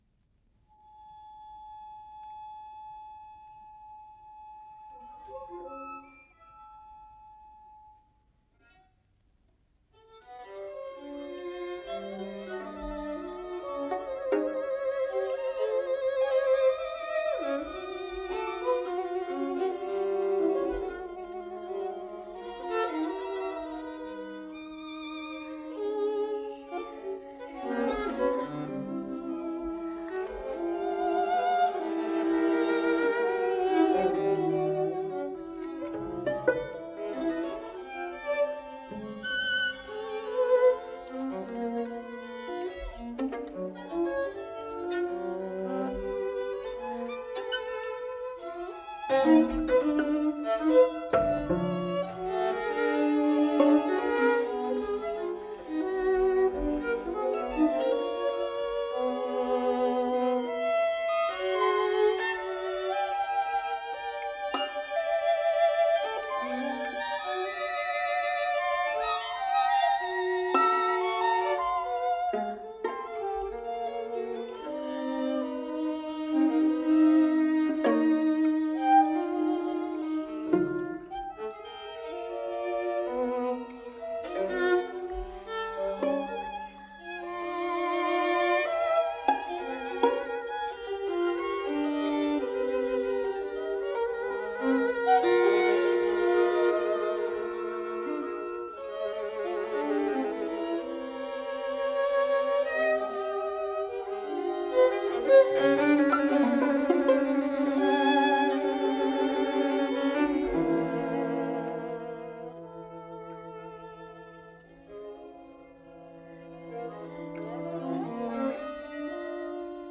Darmstadt